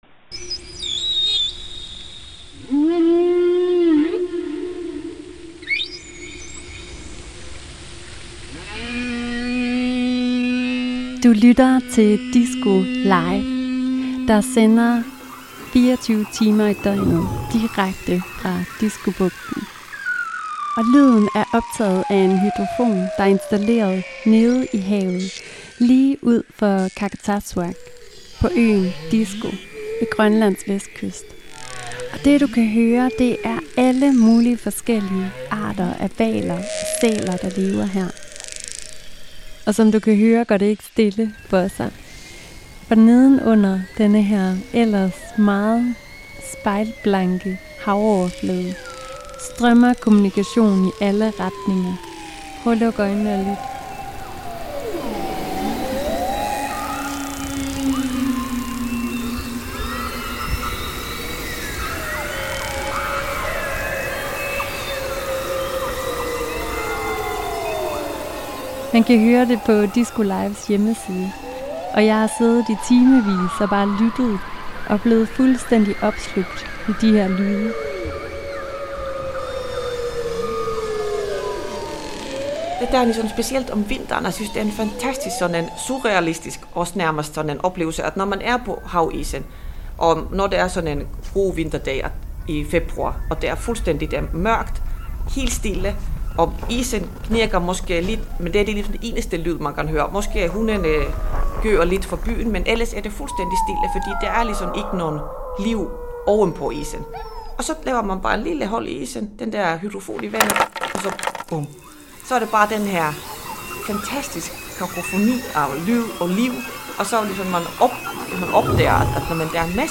I det her afsnit skal vi lytte til hvalernes sang i Diskobugten. Arktiske hvaler bruger nemlig lyde til at orientere sig i forhold til deres omgivelser, til at gå på jagt, i deres sociale liv og i forhold til at navigere i det isfyldte hav.